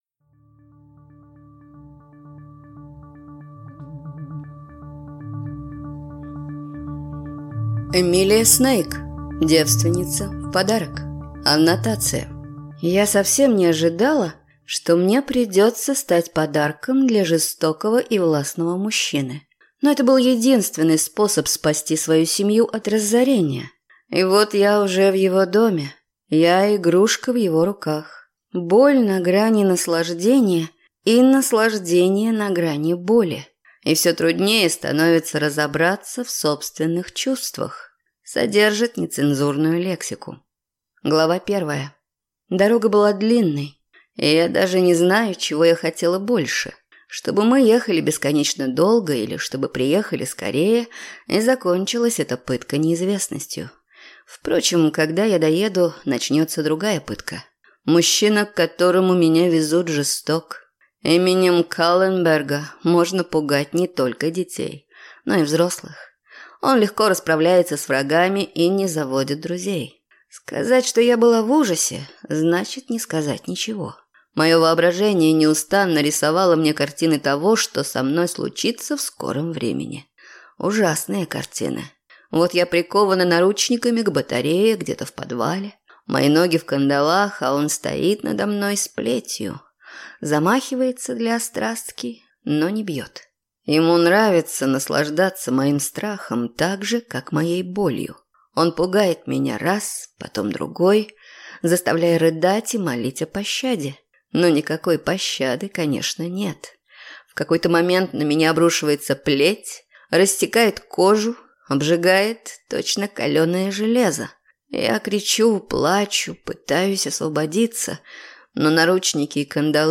Аудиокнига Девственница в подарок | Библиотека аудиокниг